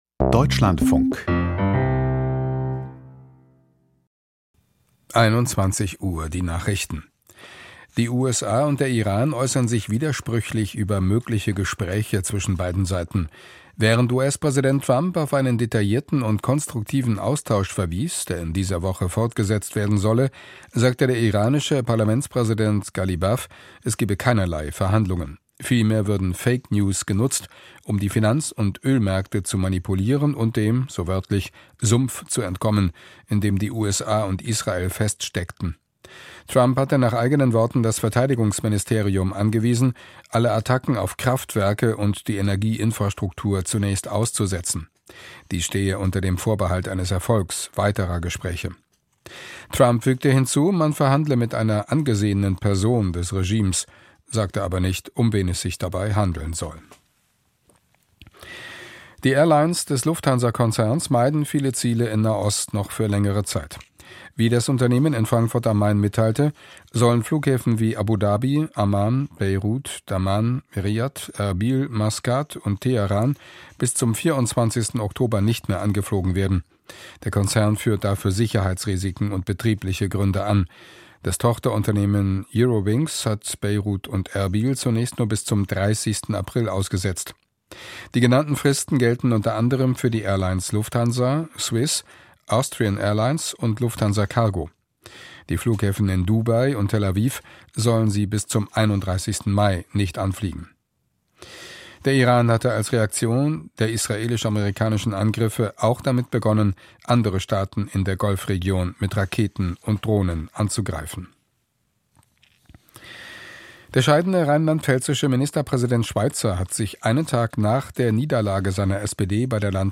Die Nachrichten vom 23.03.2026, 21:00 Uhr